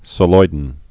(sə-loidn)